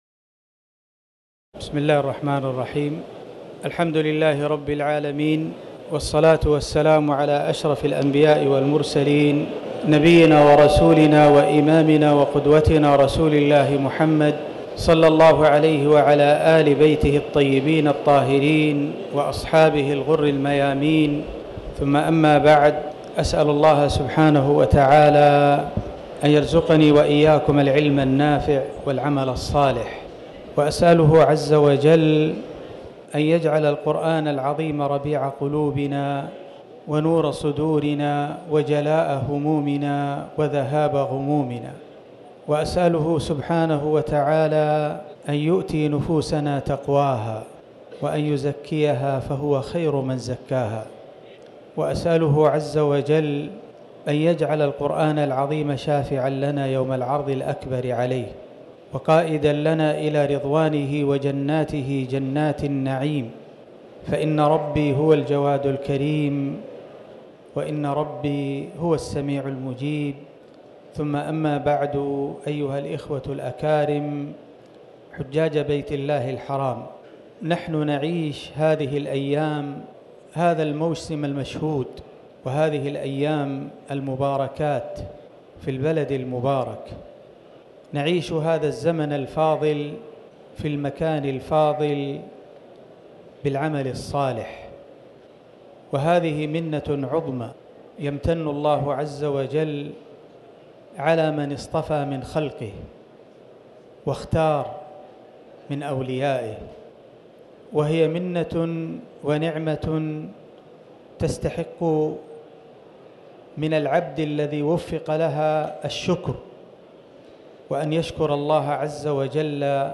محاضرة تزودوا فإن خير الزاد التقوى فيها: منزلة التقوى
تاريخ النشر ١ ذو الحجة ١٤٤٠ المكان: المسجد الحرام الشيخ
محاضرة-تزودوا-فإن-خير-الزاد-التقوى111.mp3